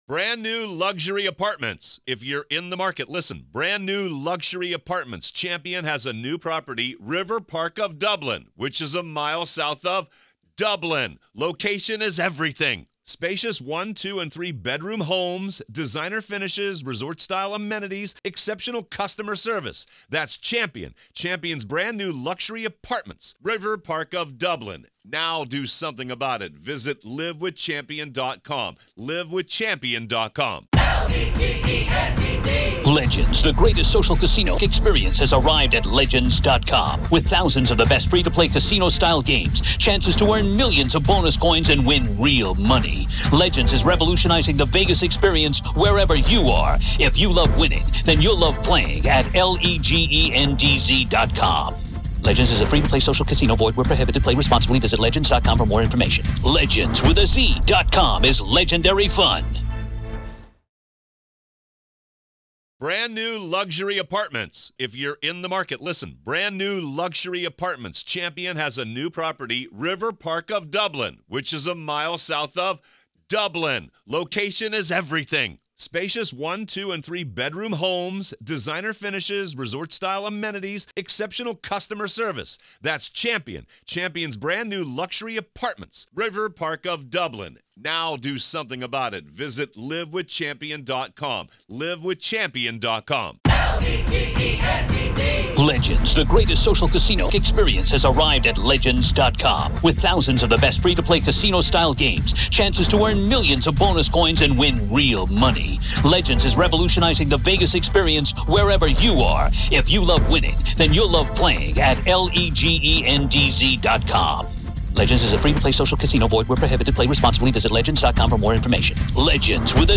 The Trial Of Lori Vallow Daybell Day 15 Part 4 | Raw Courtroom Audio